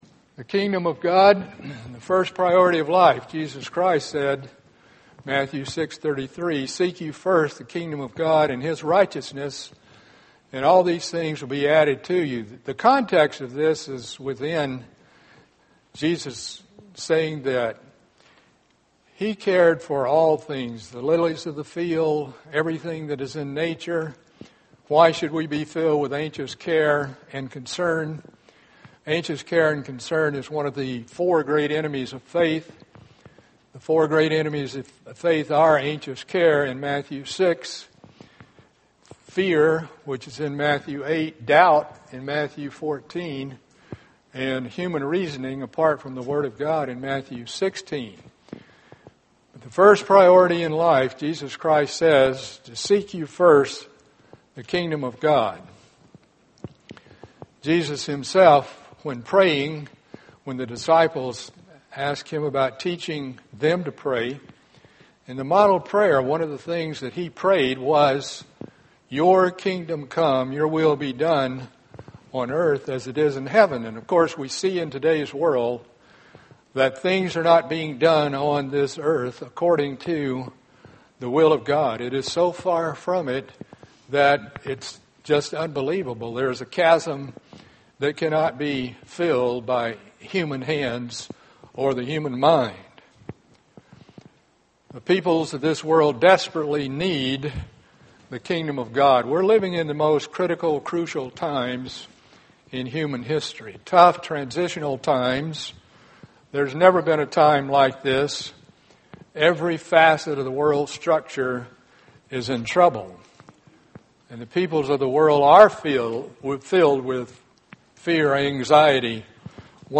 Learn more in this Kingdom of God seminar.